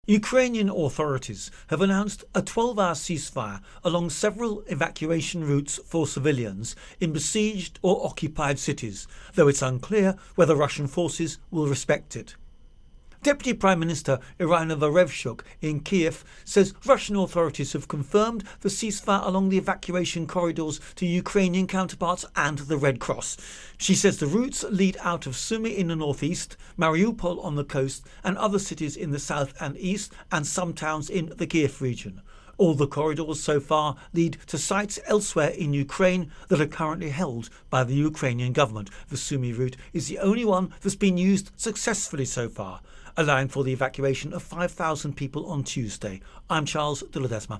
Russia-Ukraine-War- Corridors Intro and Voicer